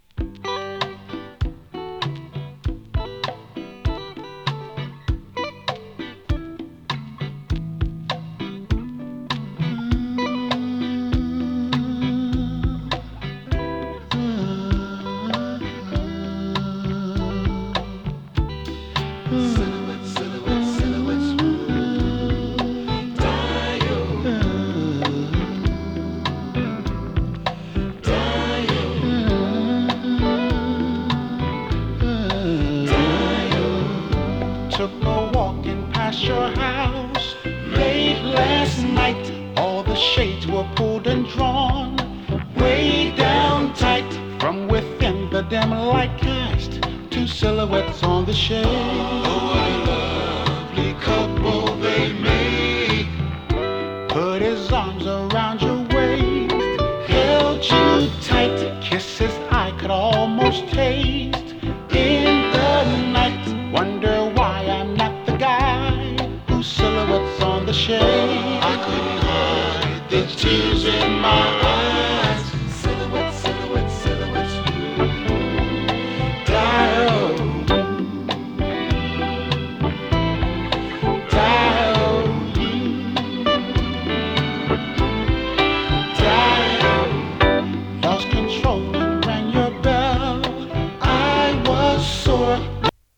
フィリーソウル